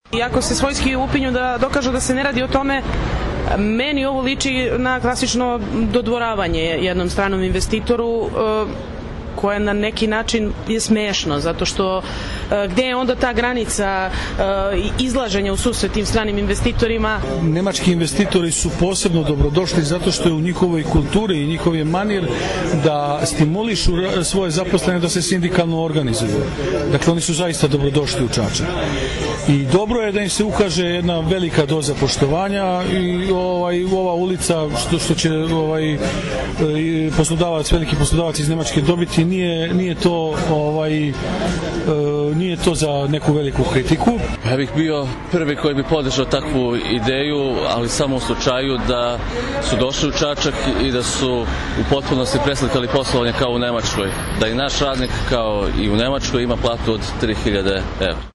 Mišljenja građana